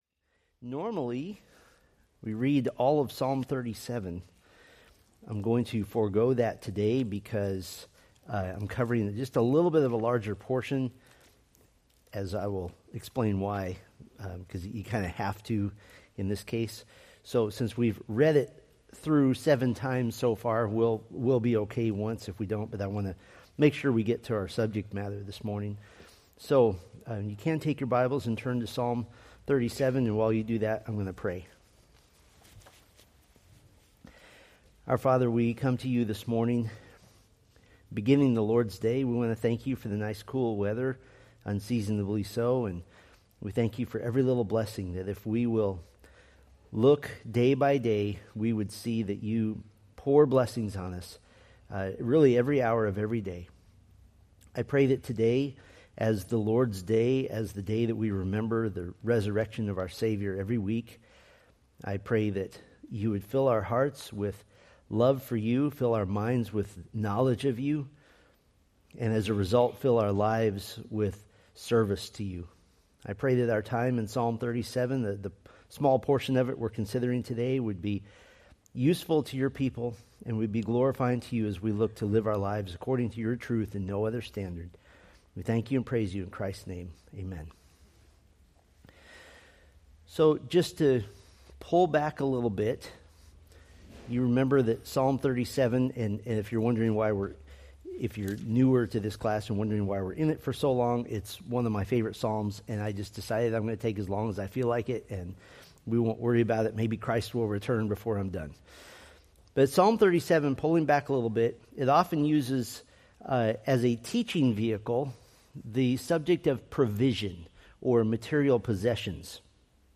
Date: May 4, 2025 Series: Psalms Grouping: Sunday School (Adult) More: Download MP3